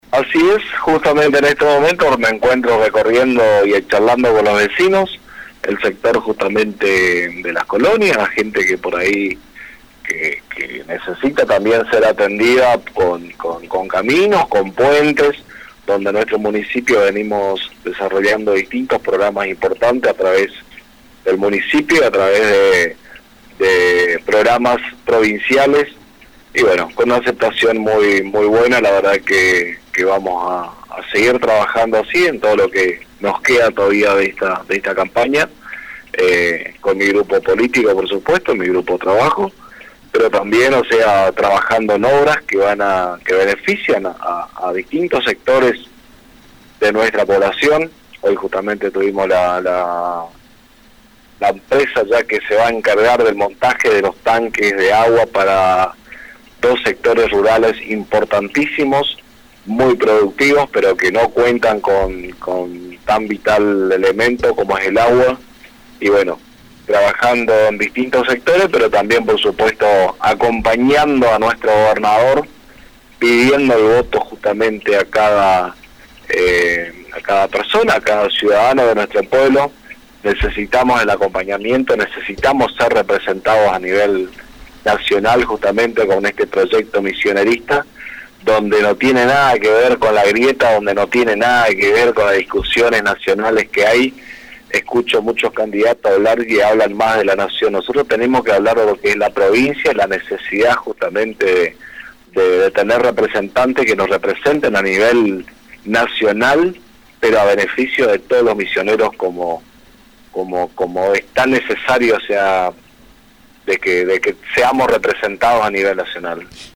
En comunicación telefónica con el Intendente de la localidad de Tres Capones